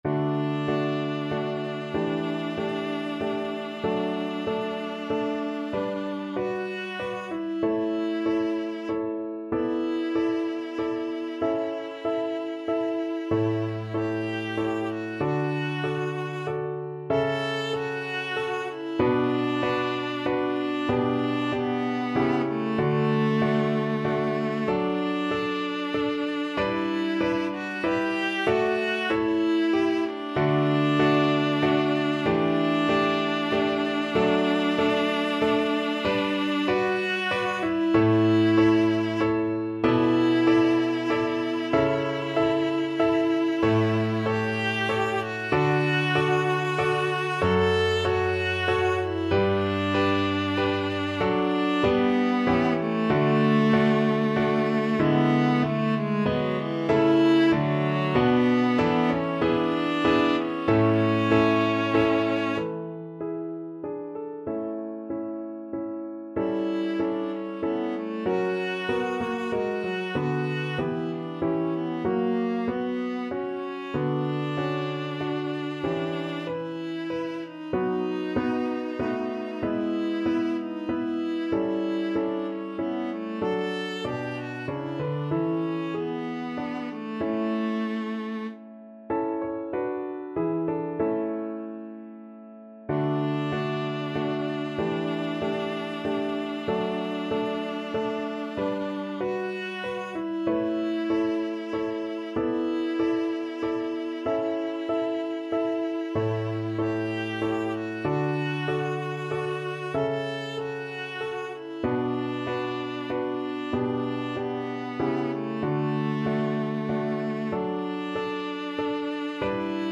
Viola
D major (Sounding Pitch) (View more D major Music for Viola )
=95 Andante
3/4 (View more 3/4 Music)
Classical (View more Classical Viola Music)